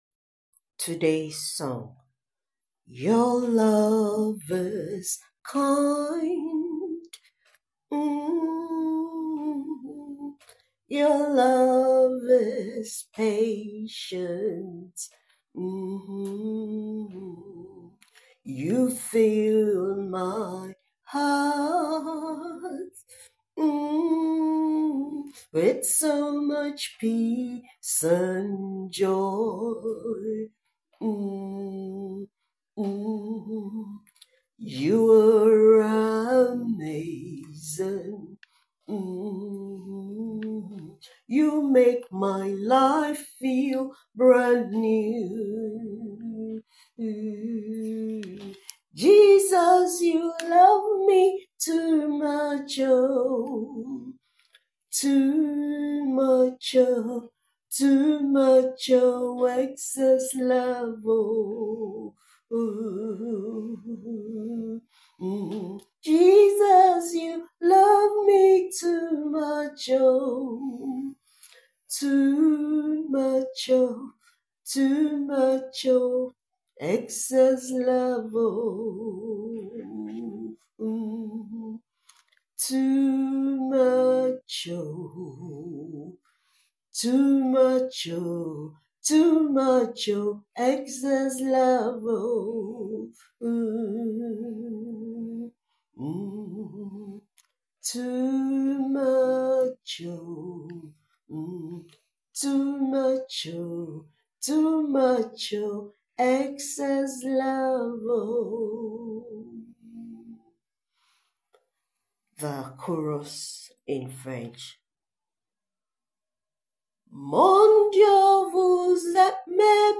Song for meditation